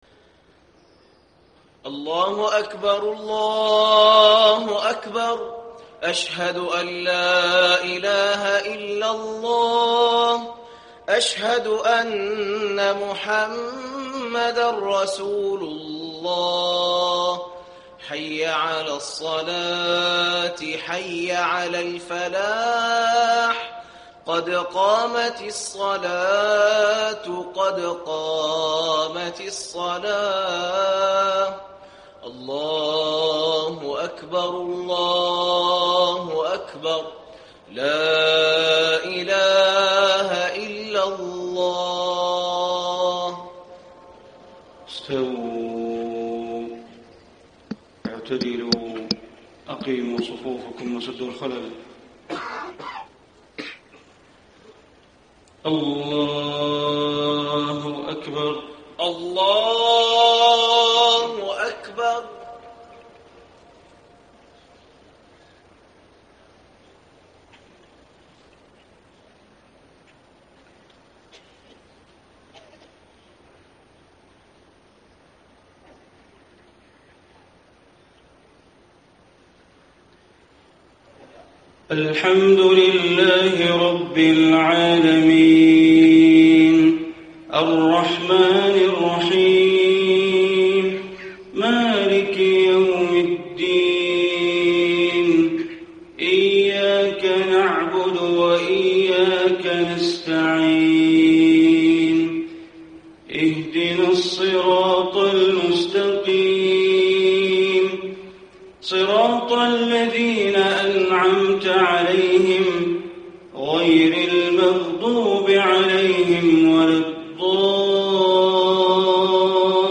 صلاة الفجر 4 - 7 - 1435هـ من سورة الأعراف > 1435 🕋 > الفروض - تلاوات الحرمين